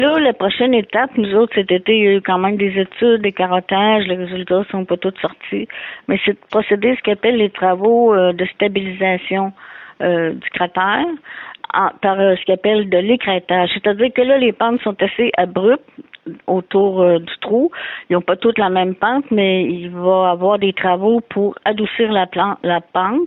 La mairesse, Denise Gendron, a résumé ce qui s’est fait au cours des derniers mois.